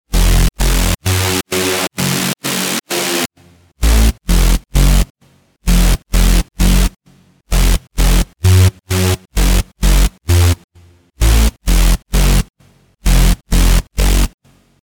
Thundering basslines, groovy rhythms, and captivating bass shots ready to elevate your sound!
Bass_House_-_BS_Loop_16_130_F.mp3